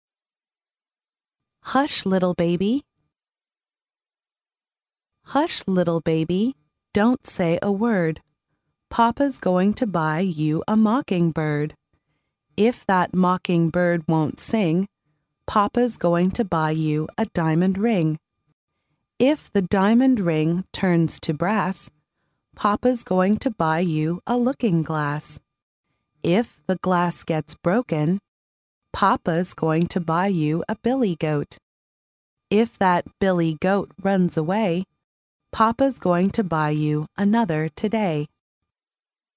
幼儿英语童谣朗读 第5期:小宝宝别说话 听力文件下载—在线英语听力室